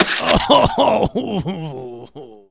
HIT04.WAV